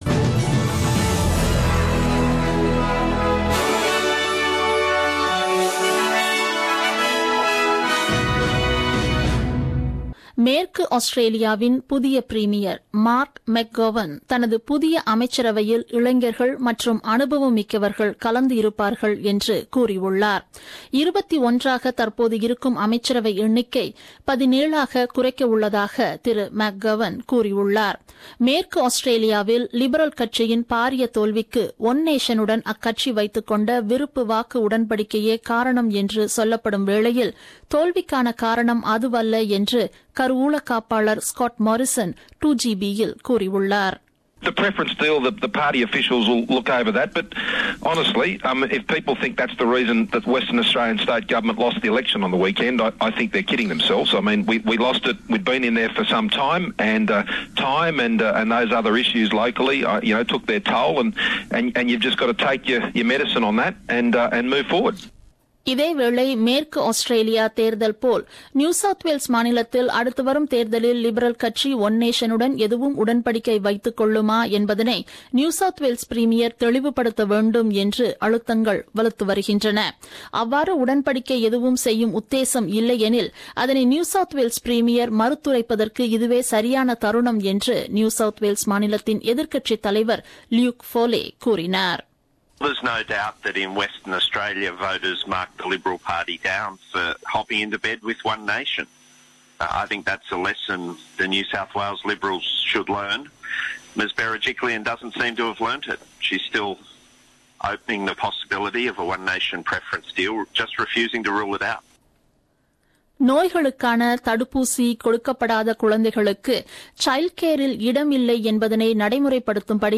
The news bulletin broadcasted on 13th March 2017 at 8pm.